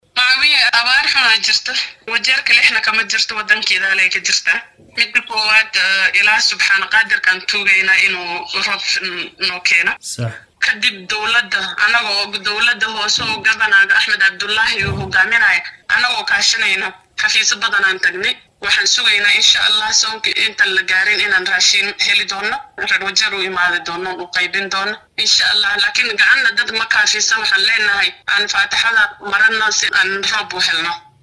Wakiilka haweenka ee ismaamulka Wajeer Faadumo Cabdi Jeexoow oo wareysi gaar ah siisay warbaahinta Star ayaa sheegtay in madaxda dowlad deegaankaasi ay iska kaashanayaan sidii bisha Ramadaan ka hor shacabka ay saameynta ku yeelatay abaarta loo gaarsiin lahaa gargaar cunno.